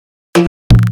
New Bassline Pack